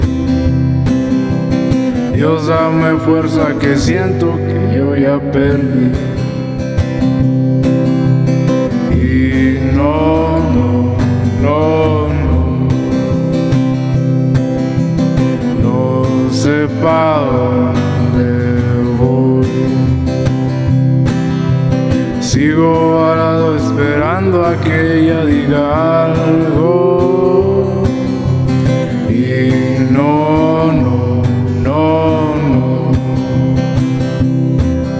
# Música Mexicana